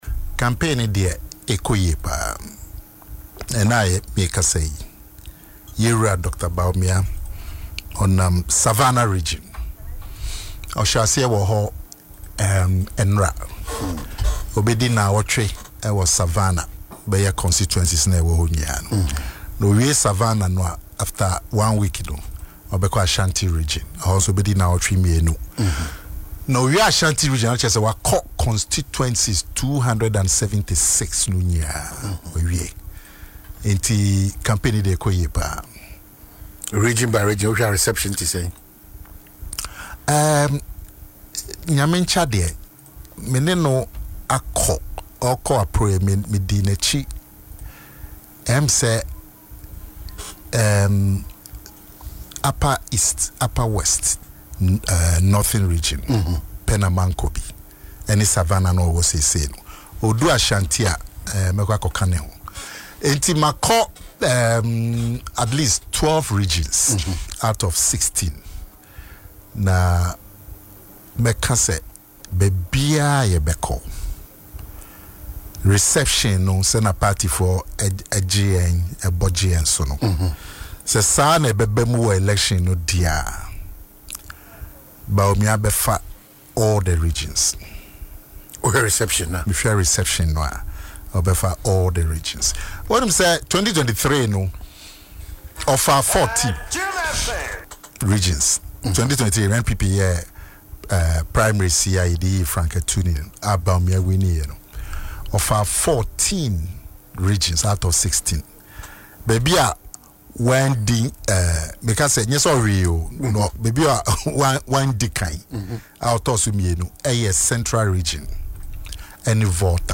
The Chairman of the Communications Directorate of Dr. Mahamudu Bawumia’s campaign, Nana Akomea, has expressed confidence that the former is poised to win all regions in the upcoming New Patriotic Party (NPP) presidential primaries. Speaking on Adom FM’s Dwaso Nsem morning show, Nana Akomea said (…)